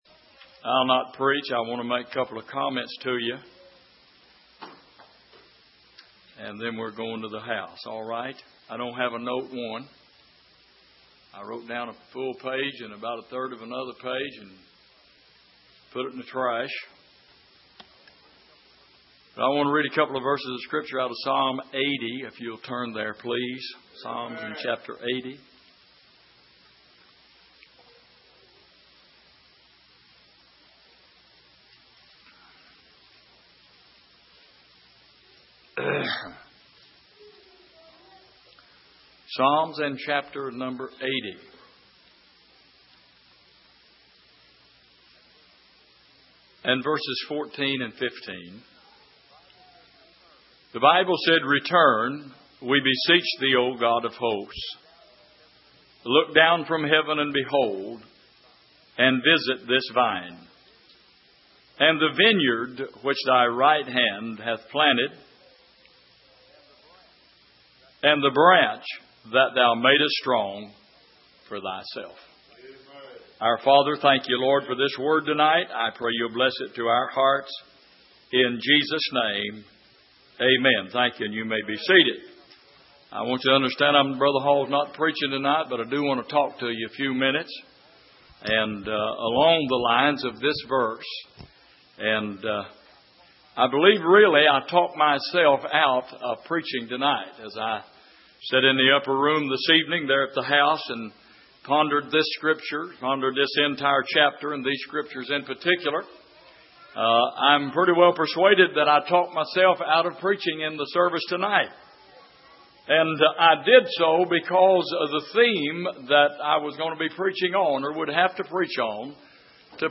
Passage: Psalm 80:14-15 Service: Sunday Evening